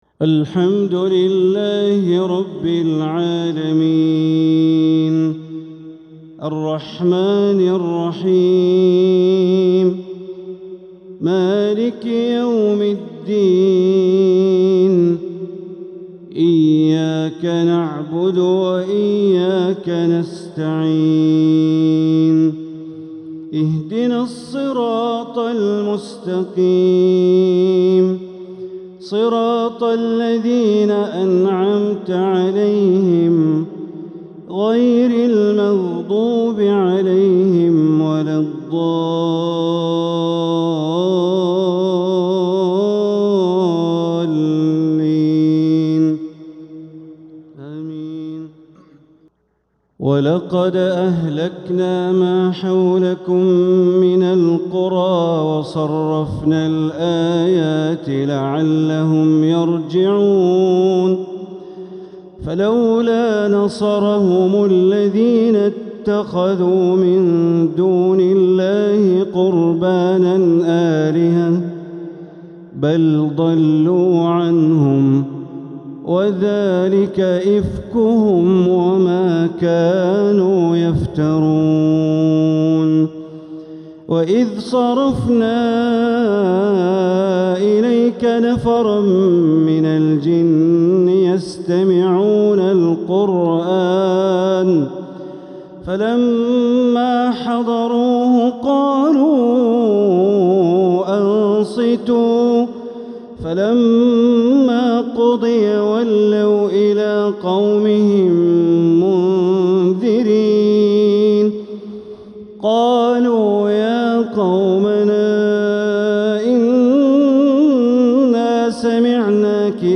(ويوم يعرض الذين كفروا على النار) تلاوة خاشعة من سورة الأحقاف | الشيخ بندر بليلة | ٢٥ رجب ١٤٤٧ هـ > 1447هـ > الفروض - تلاوات بندر بليلة